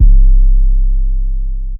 808 for cooking up with trav.wav